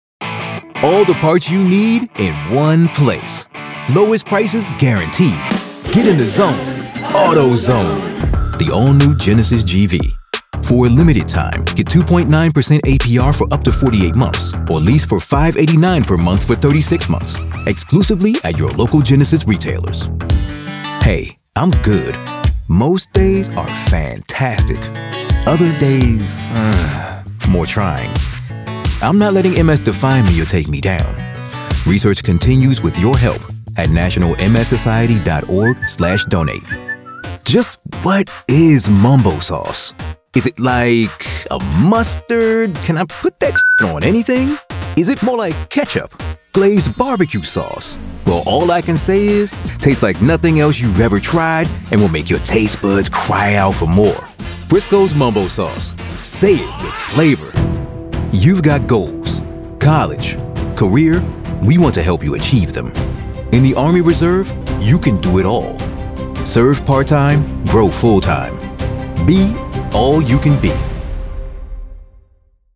Hire Deep Voice Actors For Your Project
English (American)
Confident
Commanding
Knowledgeable